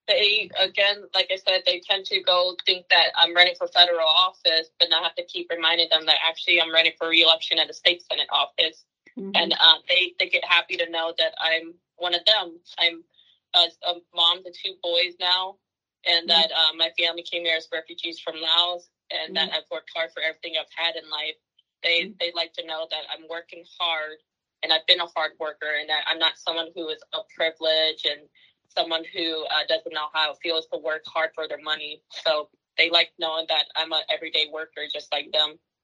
ທ.ນ. ທີນາ ມະຫາລາດ ກ່າວກ່ຽວກັບການໄປໂຄສະນາຫາສຽງໃນເຂດເລືອກຕັ້ງຂອງຕົນ